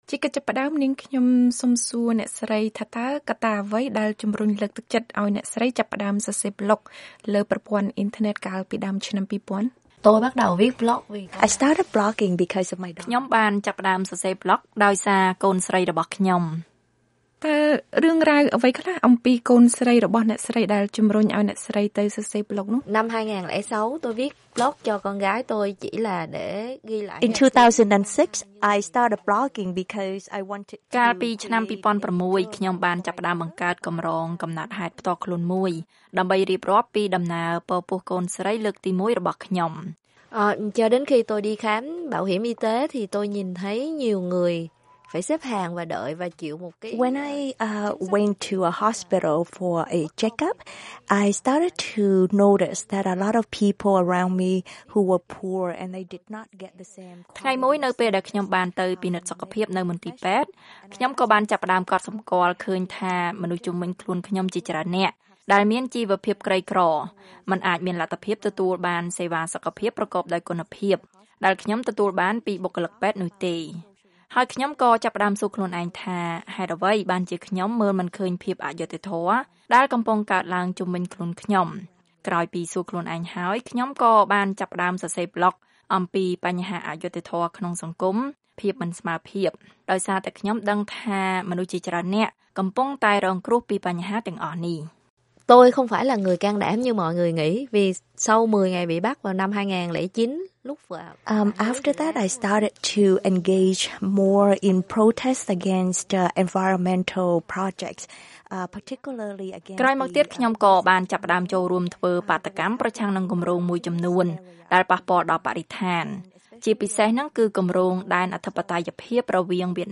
ថ្មីៗនេះ អ្នកស្រី Quynh បានធ្វើដំណើរមកកាន់រដ្ឋធានីវ៉ាស៊ីនតោន ក្នុងនាមជាបេក្ខជនម្នាក់ដែលទទួលពានរង្វាន់សេរីភាពសារព័ត៌មានអន្តរជាតិពីគណៈកម្មការការពារអ្នកសារព័ត៌មាន (The Committee to Protect Journalists) ហើយបានផ្តល់បទសម្ភាសន៍ដល់ VOA Khmer។